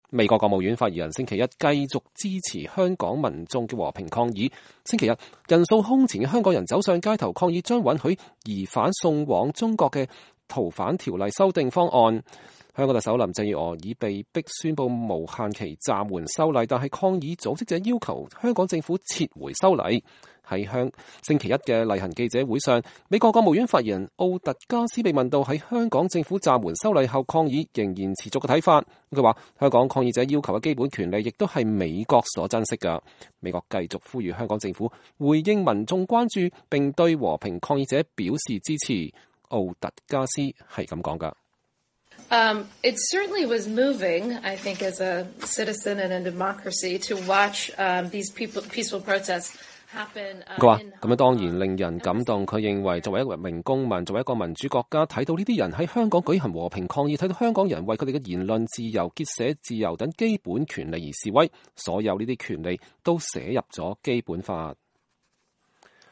美國國務院發言人奧特加斯(Morgan Ortagus)在美國時間星期一(6月17日)的國務院記者會上評論香港在6月16日兩百萬人上街抗議要求香港當局撤回逃犯條例修訂案﹐重申支持週日港人對《逃犯條例》表達的關注。